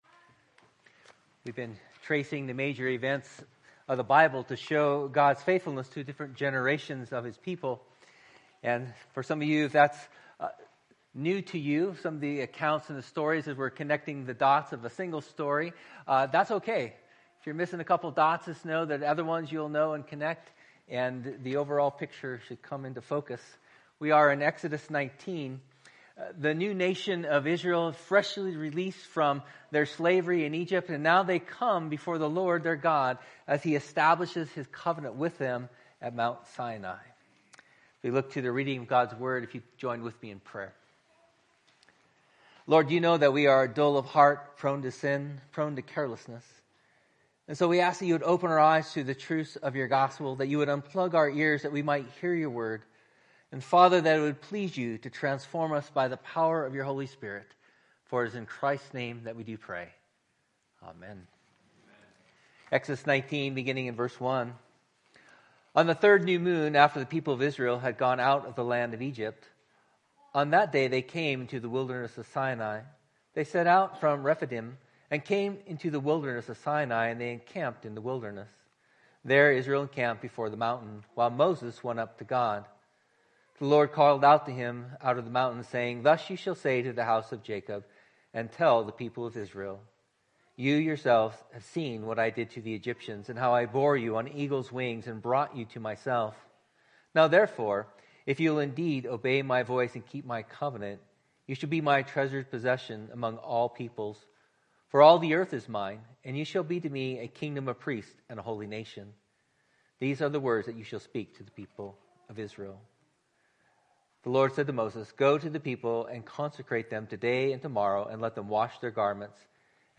Type Morning